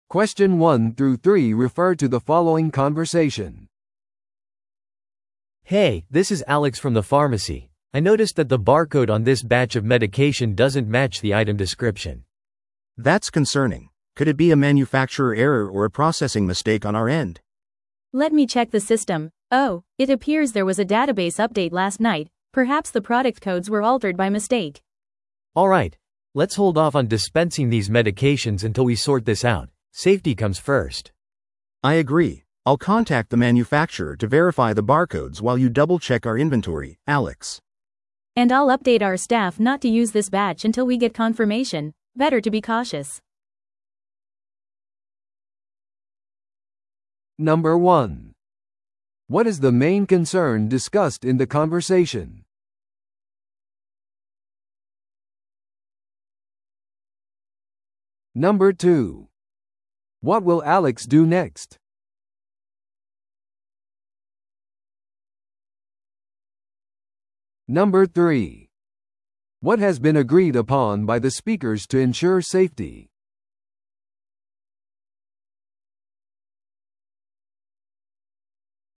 No.1. What is the main concern discussed in the conversation?